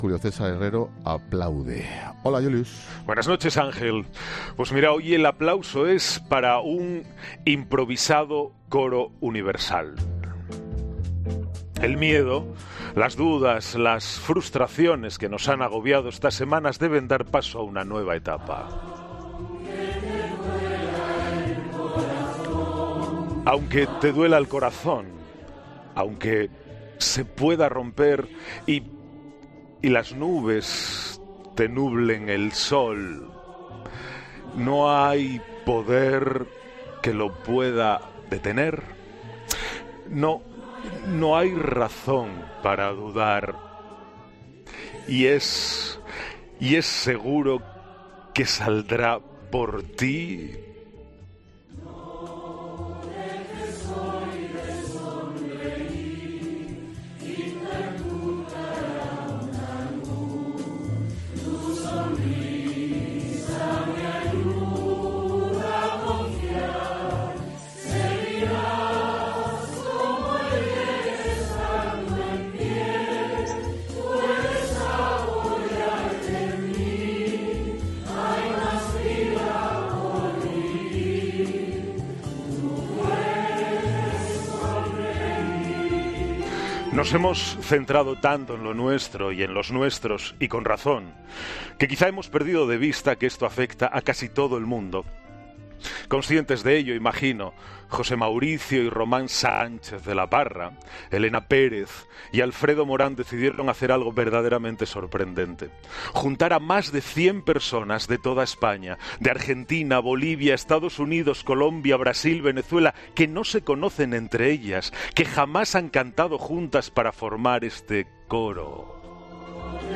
Más de cien personas de España, Argentina, Bolivia, Estados Unidos, Colombia, Brasil o Venezuela, que no se conocen, forman este emocionante coro